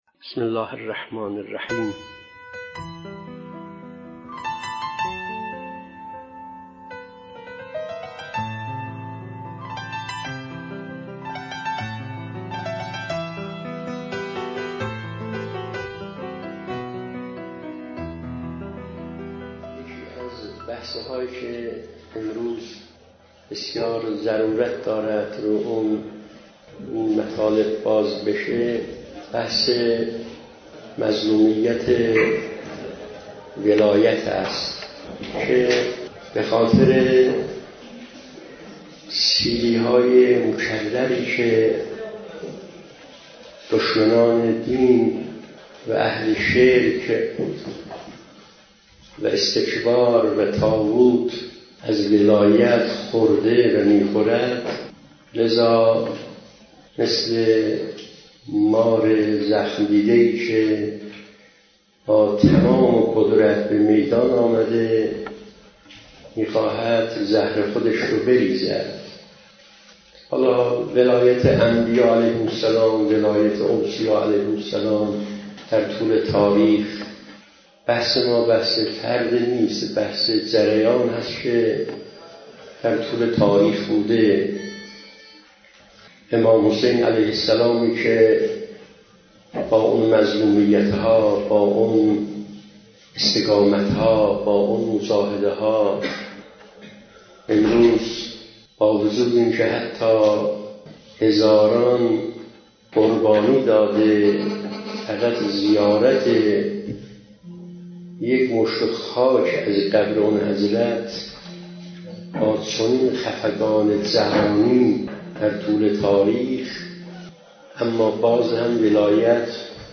این سخنرانی تحلیلی ریشه‌های فکری، تاریخی و اجتماعی این دشمنی را واکاوی می‌کند.